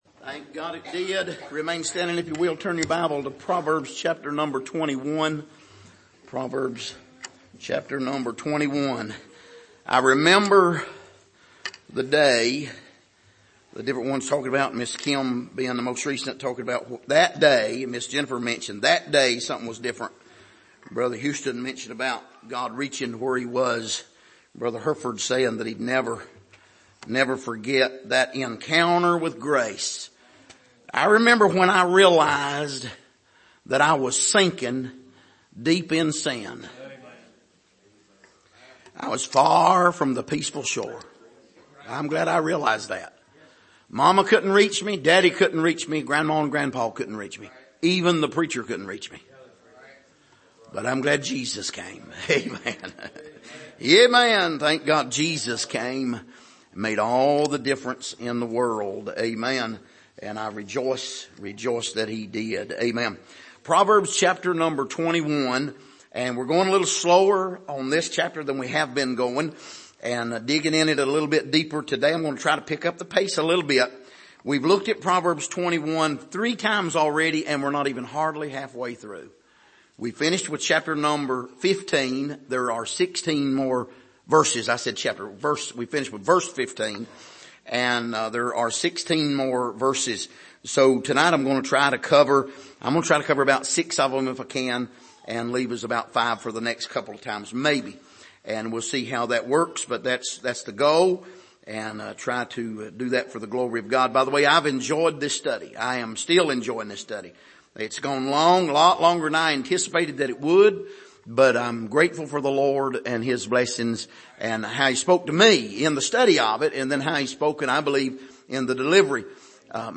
Passage: Proverbs 21:16-21 Service: Sunday Evening